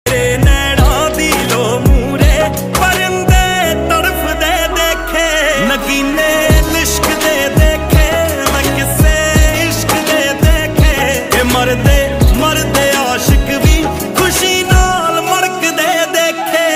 infusing the song with raw emotion and depth.
piano and keys